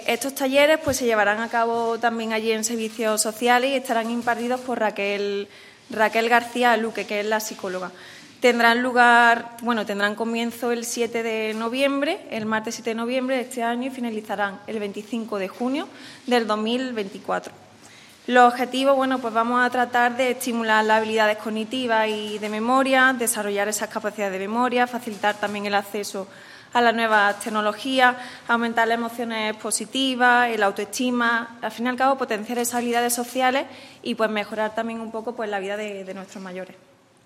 La concejal delegada de Familia y Mayores, María Sierras, ha presentado hoy en rueda de prensa el Programa Municipal de Actividades para Personas Mayores que todos los años se impulsa desde el Ayuntamiento de Antequera con el objetivo de tratar de mejorar la vida y el ocio de las personas mayores de 60 años.
Cortes de voz